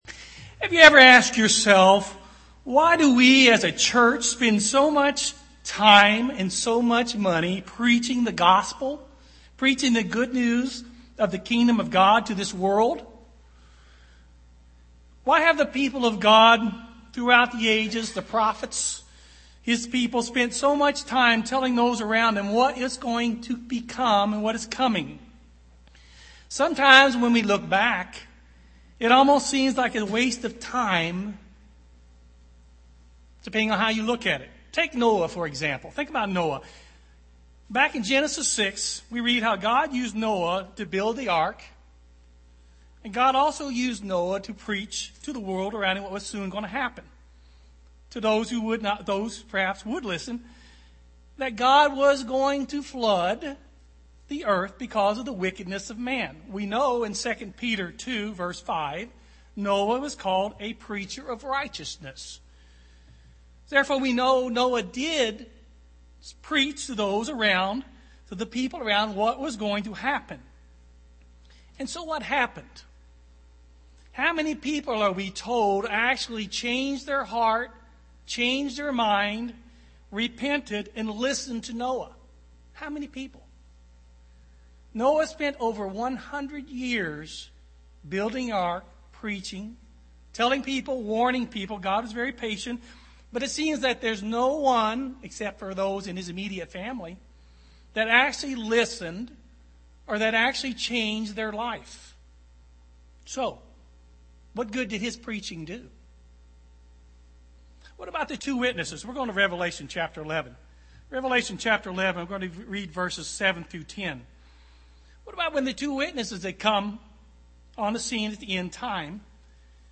UCG Sermon preach the gospel Ezekiel Studying the bible?